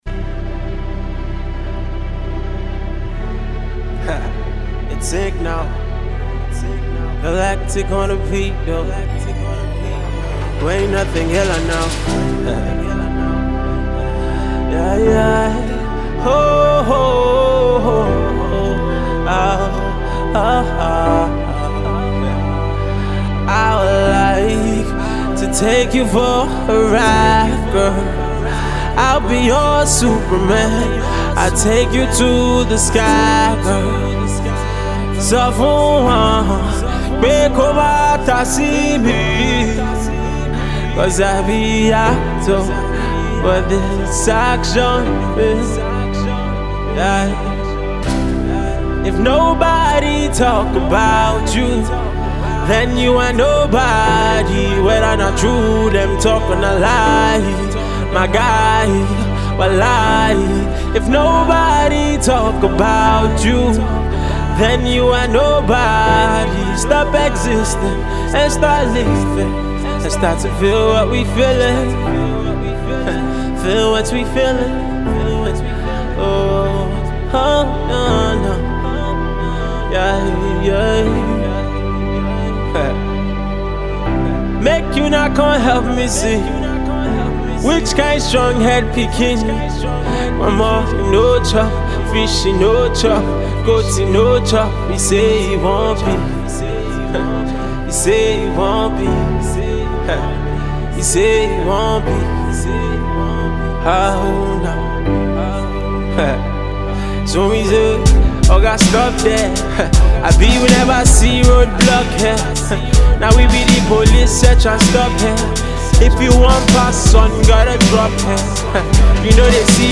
medley/fusion